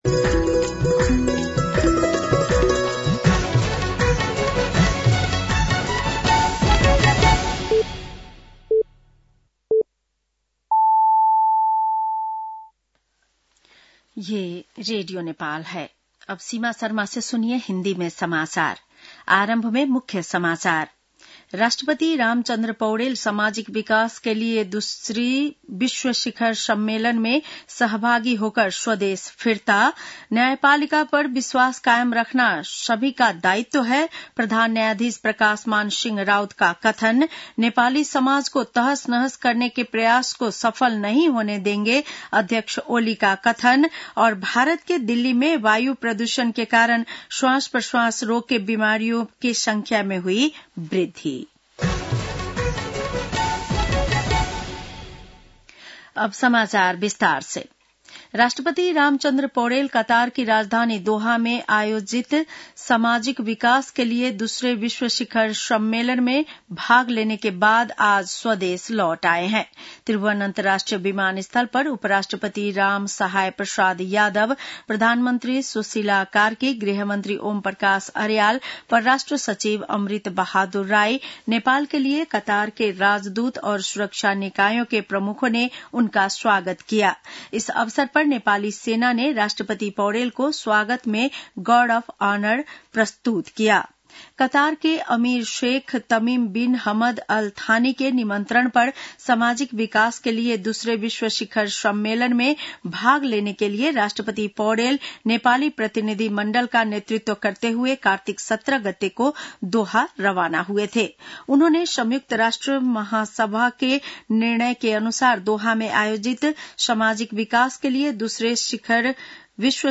बेलुकी १० बजेको हिन्दी समाचार : २० कार्तिक , २०८२
10-PM-Hindi-NEWS-7-20.mp3